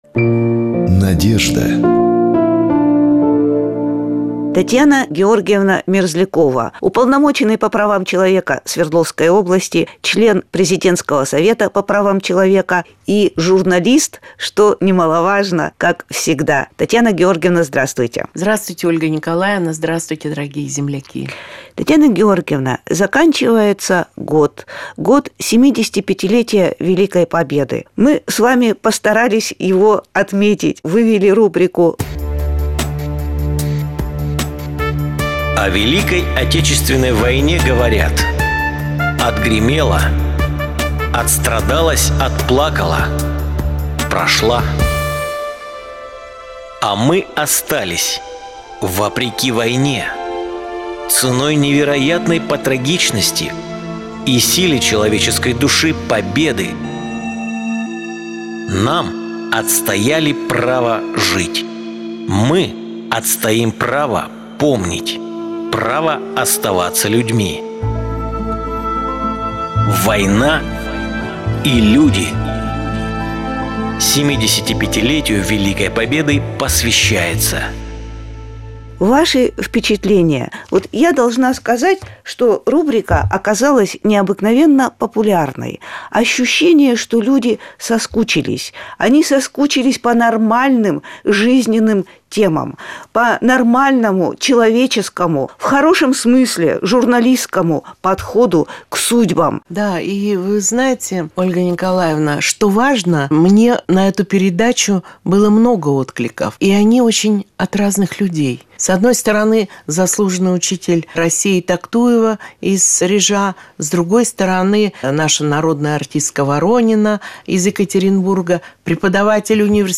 На почту нашего интернет-радио “Русский Альянс”пришло послание в виде аудиозаписи одной из программ Екатеринбургского радио “Урал”.